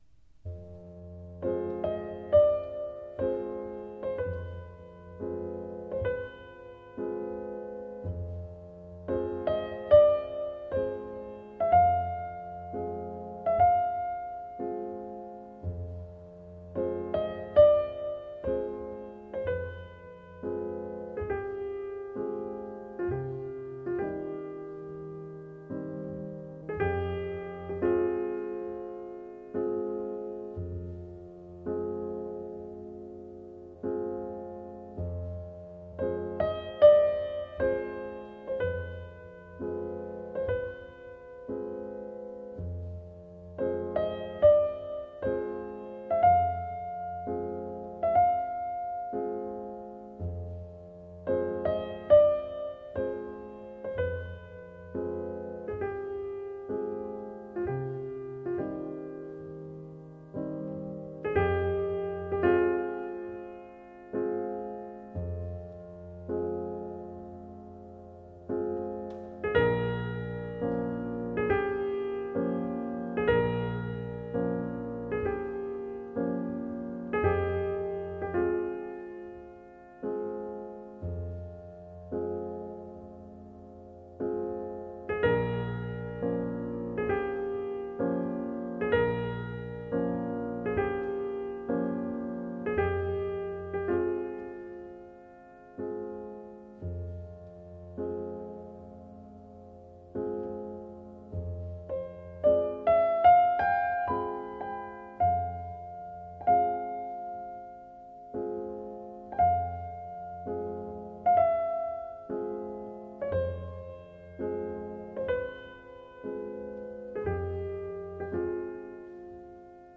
классическая музыка, фортепьянная музыка